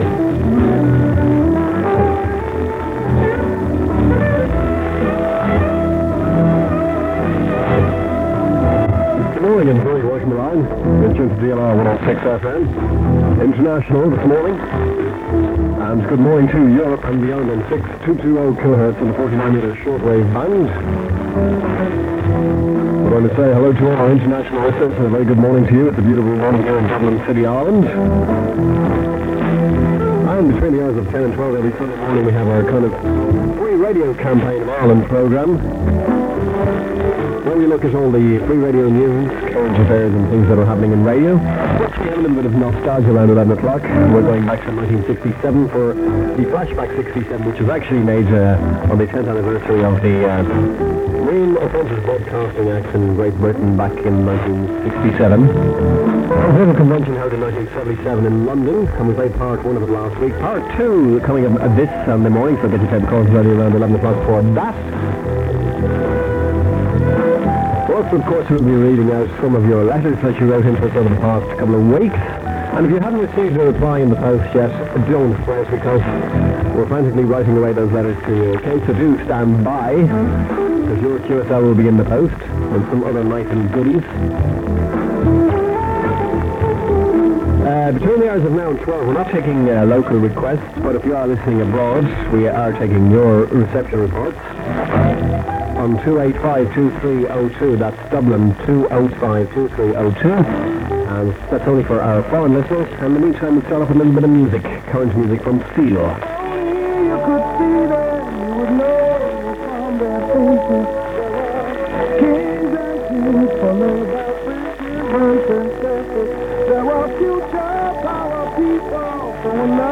From 10am, the first few minutes of the dx show with listeners letters as received near Blackpool on 6220kHz. Some shortwave fading and a little ssb interference as normal. 37MB 40mins 1992_08_30_sun_dlr_6220sw_1020-1105-signon_dx_show-gh268.mp3 New May 2022 A late sign-on for the live dx show at 1017am as received near Blackpool on 6220kHz.